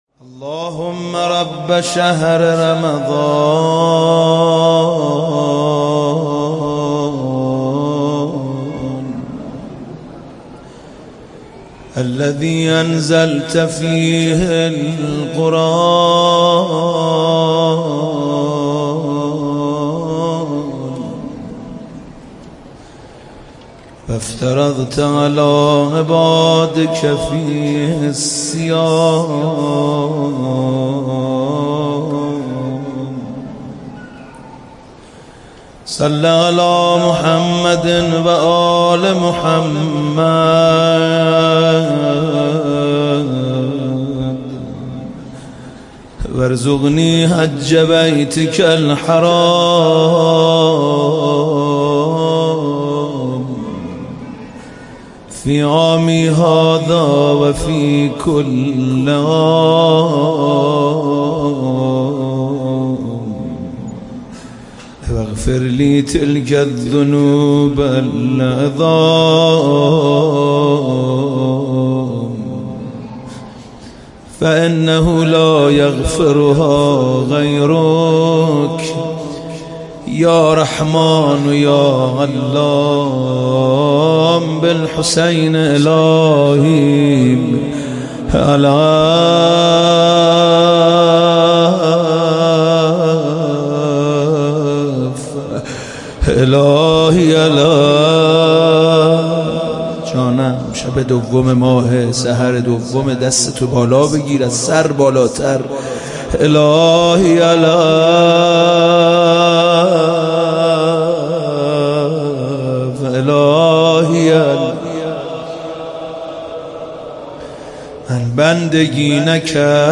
شب دوم رمضان هیات مکتب الصادق (ع) سه شنبه 17 اردیبهشت 1398